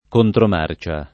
vai all'elenco alfabetico delle voci ingrandisci il carattere 100% rimpicciolisci il carattere stampa invia tramite posta elettronica codividi su Facebook contromarcia [ kontrom # r © a ] (raro contrammarcia ) s. f.; pl. ‑ce